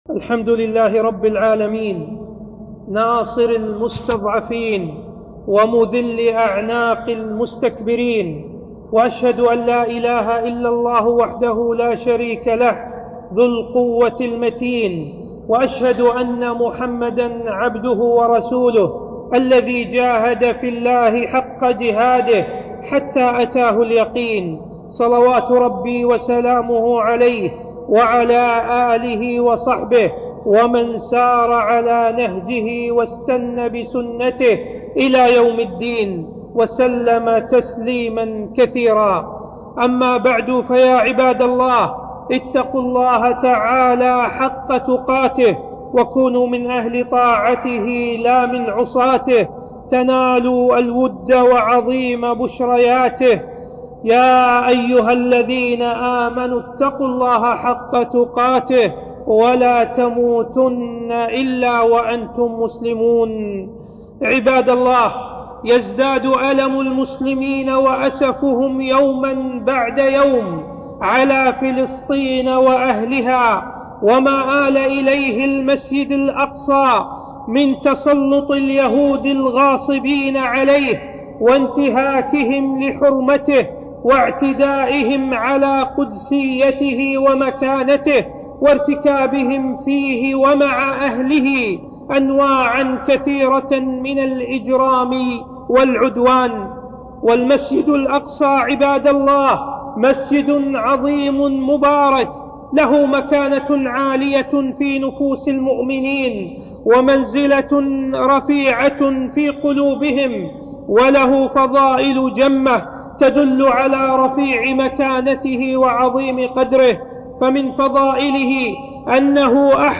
خطبة - واجبنا تجاه الأقصى وفلسطين